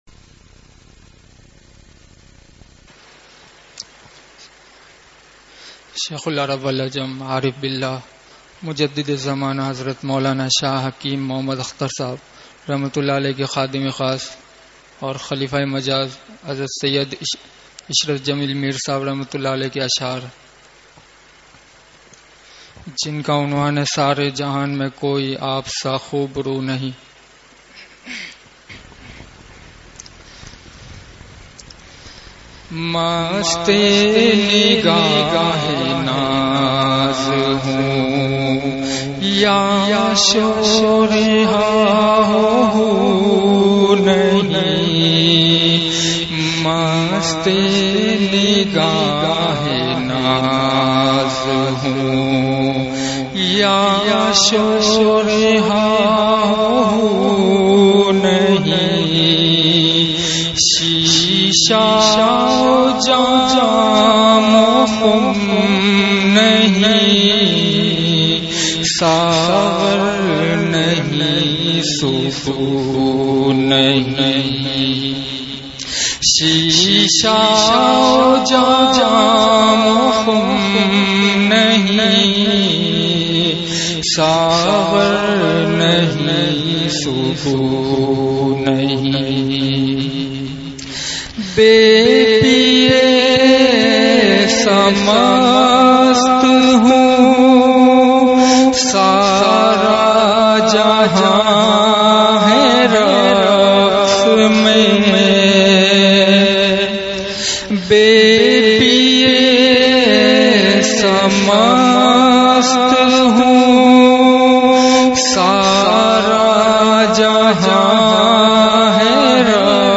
حضرت کا مدرسہ اصحاب کہف گودھرا میں بیان